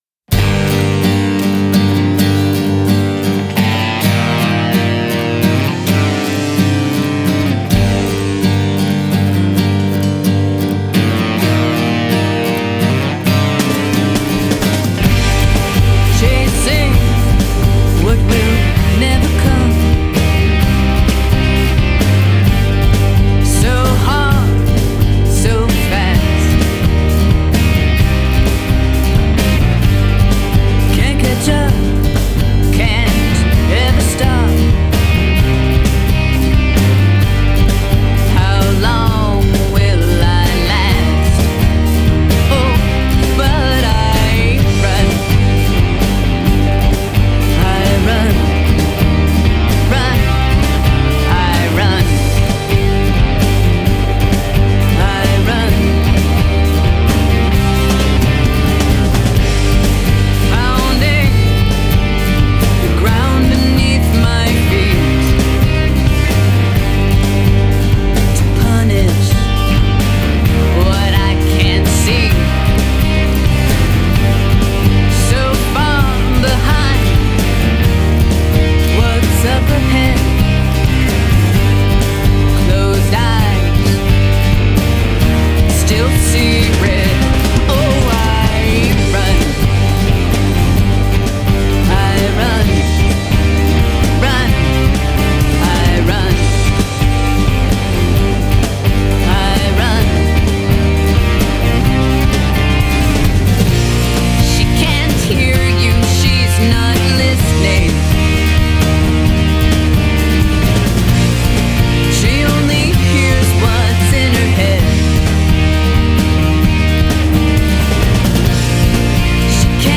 Genre: Pop, Rock